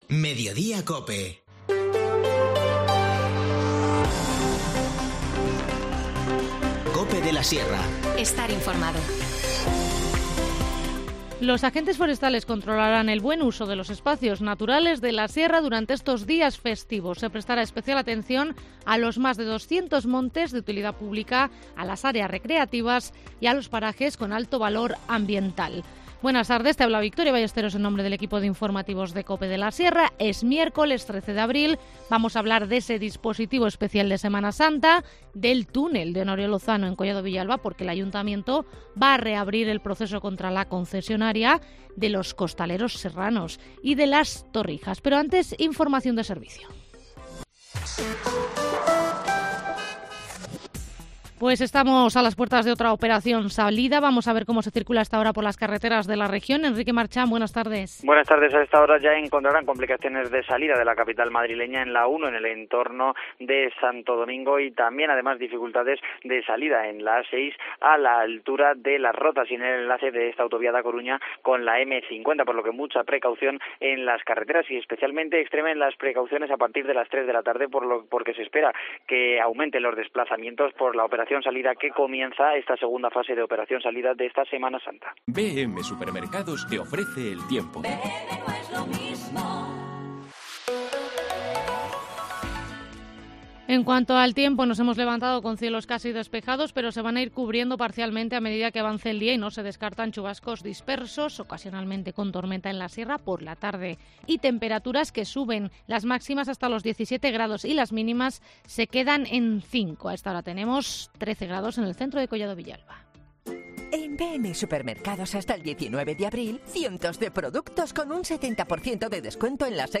Informativo Mediodía 13 abril